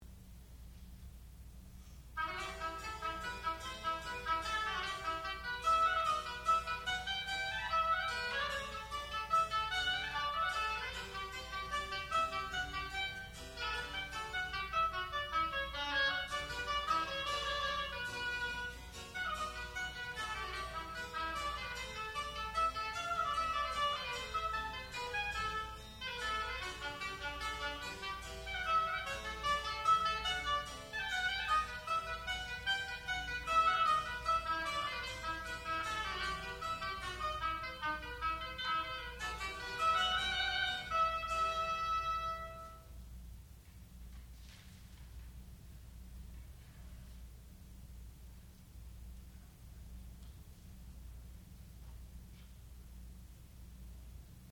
classical music
oboe
harpsichord
Graduate Recital